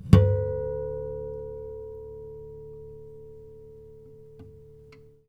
harmonic-11.wav